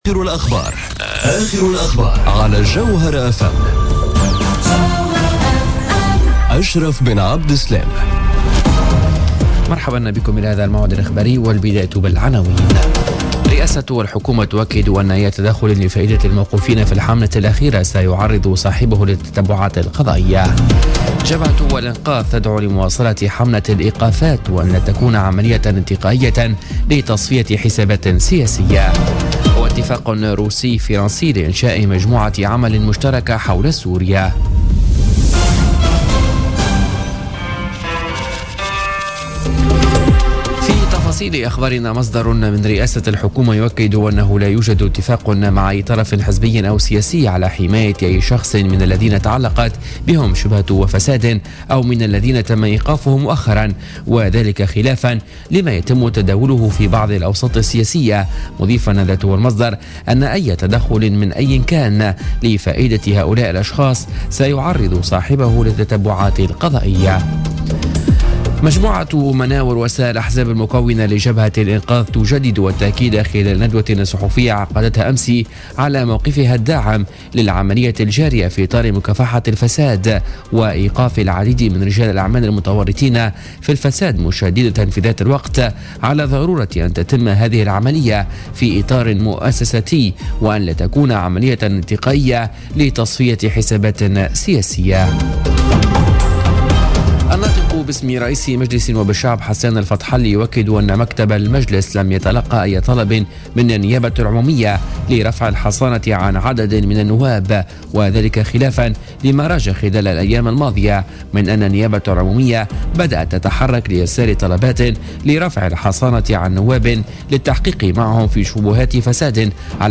نشرة أخبار منتصف الليل ليوم الثلاثاء 30 ماي 2017